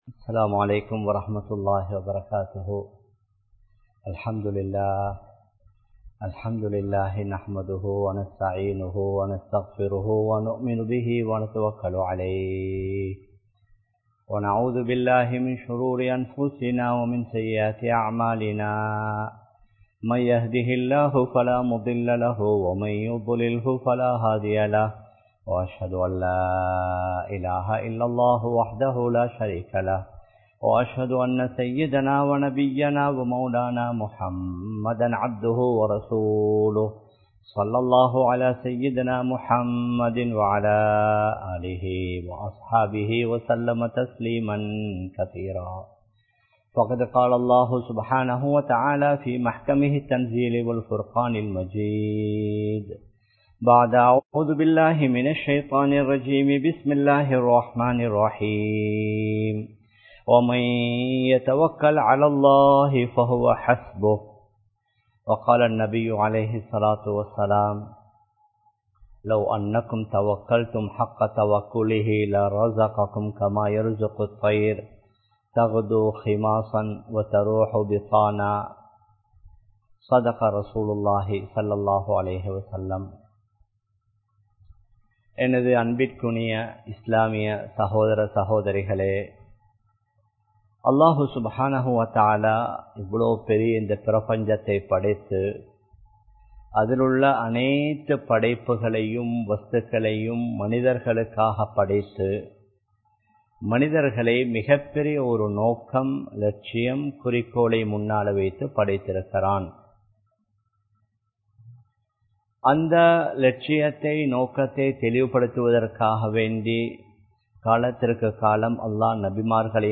Allahvai Uruthiyaha Nambungal (அல்லாஹ்வை உறுதியாக நம்புங்கள்) | Audio Bayans | All Ceylon Muslim Youth Community | Addalaichenai
Live Stream